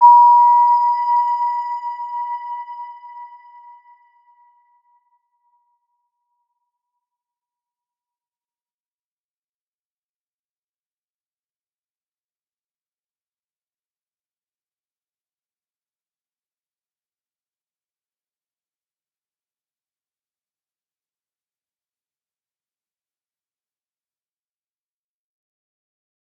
Round-Bell-B5-f.wav